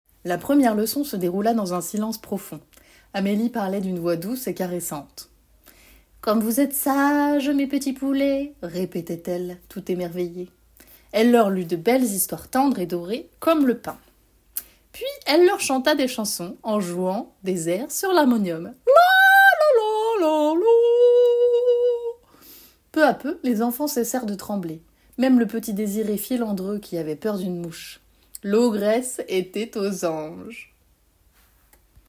27 - 49 ans - Mezzo-soprano